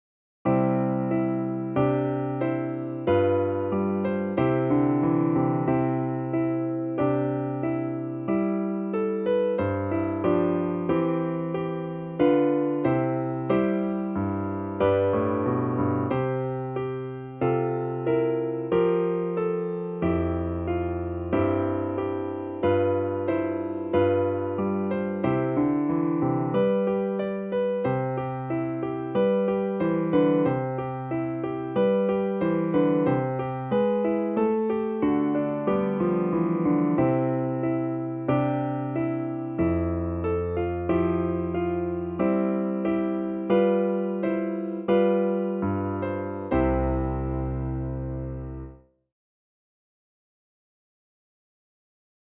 DIGITAL SHEET MUSIC - FLUTE with PIANO ACCOMPANIMENT
Flute Solo, Classical
piano with slower practice version and faster performance